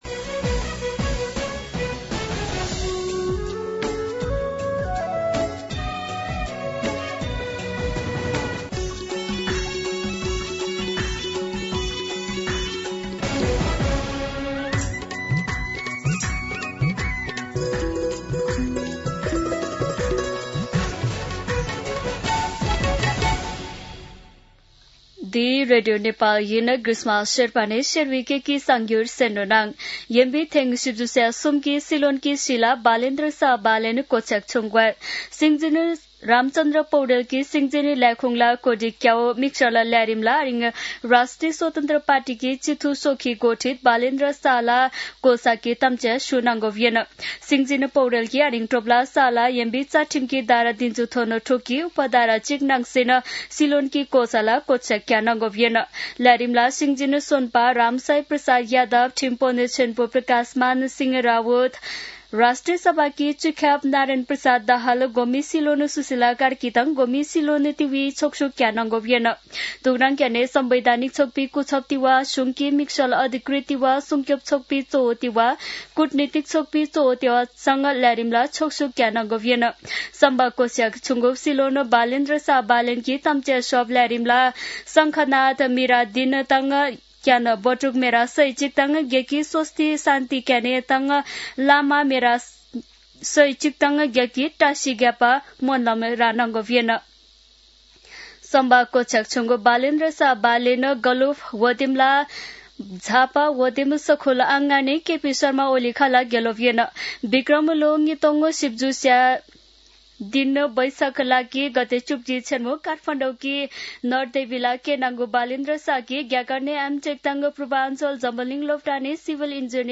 शेर्पा भाषाको समाचार : १३ चैत , २०८२
Sherpa-News-2.mp3